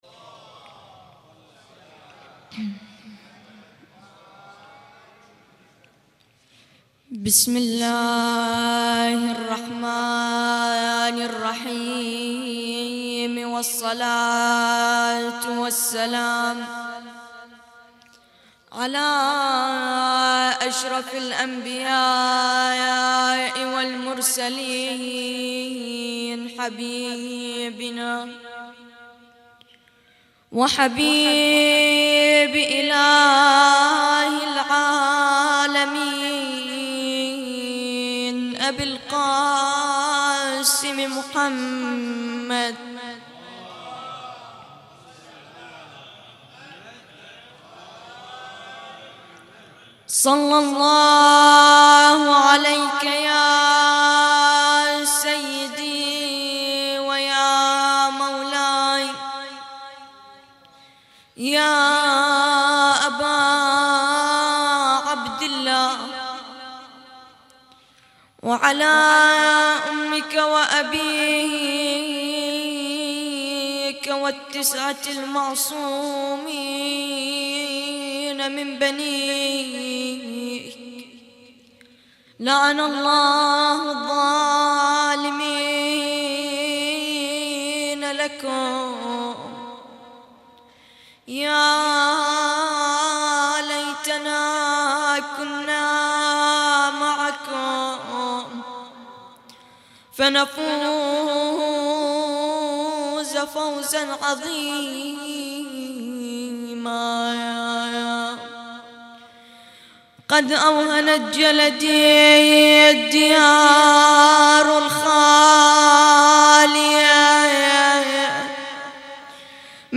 تغطية صوتية: يوم خامس محرم 1438هـ في المأتم
يوم 5 محرم 1438هـ - مجلس العزاء